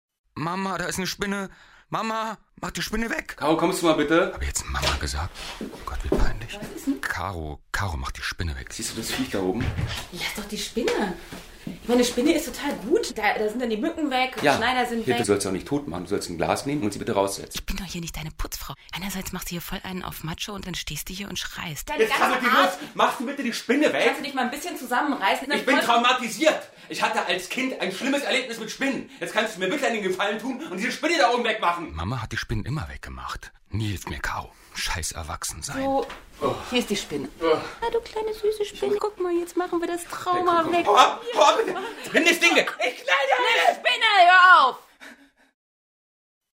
Minihörspiele, die für abwegige Gedanken, neue Wahrnehmungen und intelligenten Humor werben.